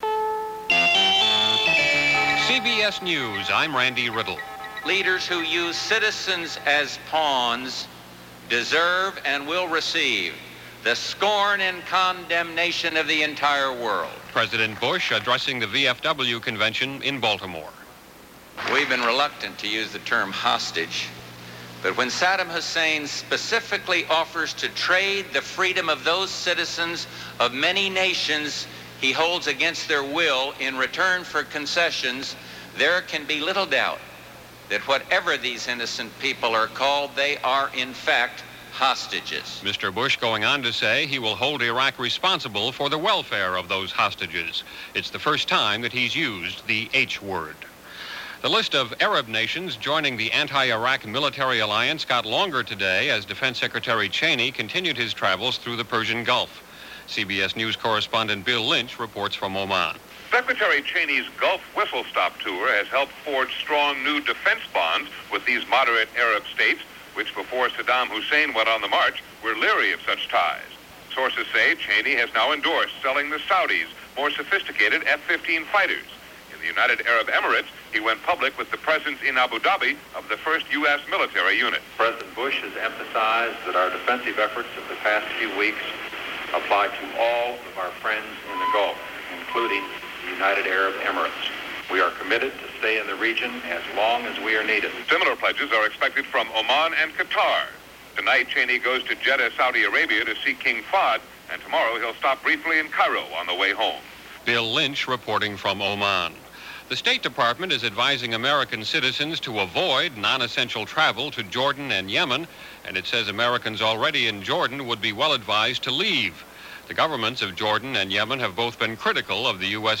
And that’s just a little of what went on, this August 20, 1990 as reported by CBS Radio during their Hourly News and Special Reports throughout the day.